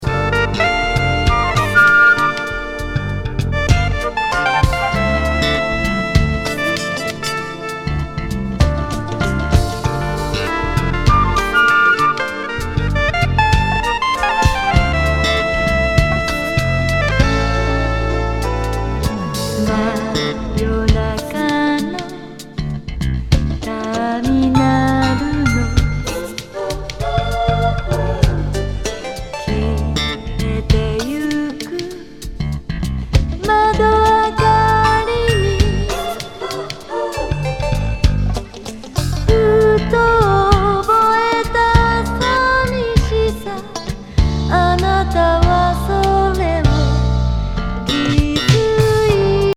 和レゲAOR